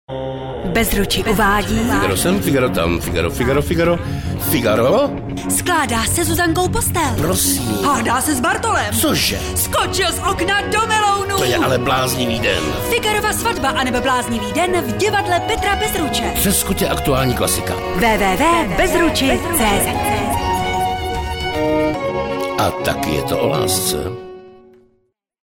audioupout�vka